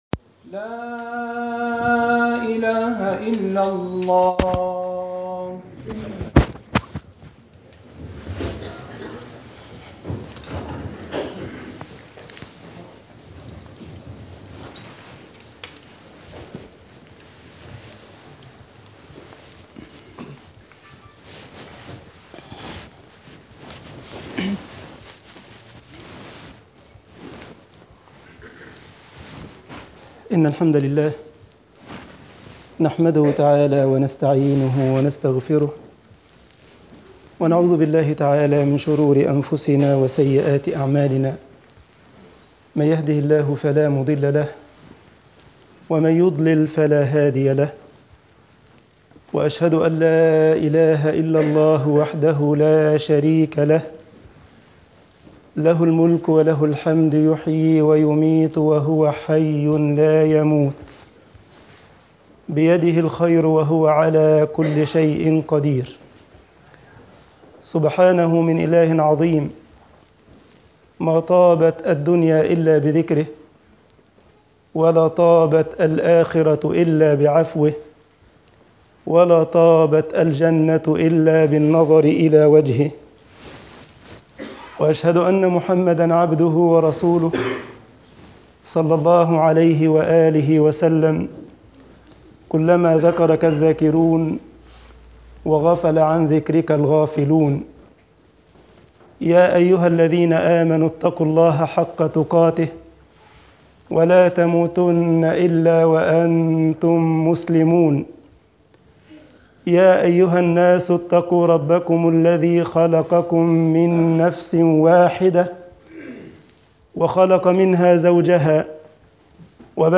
Freitagsgebet_al esmat min fetnat al-ilm8.mp3